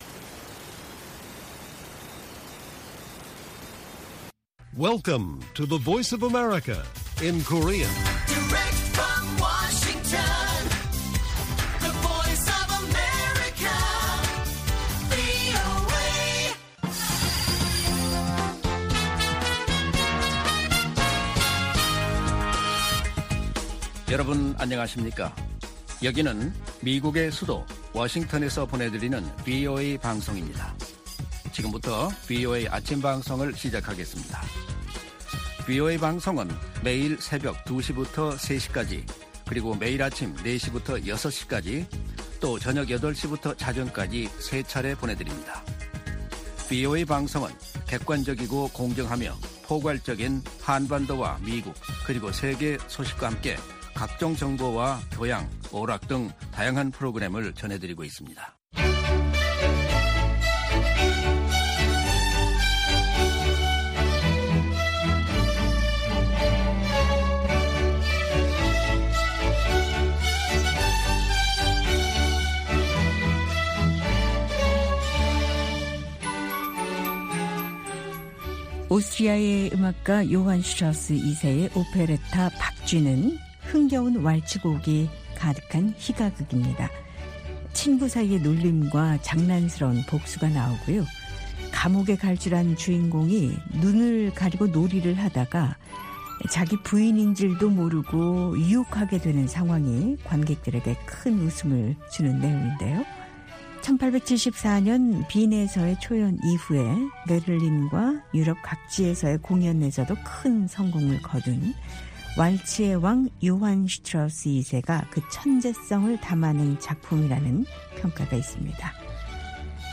VOA 한국어 방송의 일요일 오전 프로그램 1부입니다. 한반도 시간 오전 4:00 부터 5:00 까지 방송됩니다.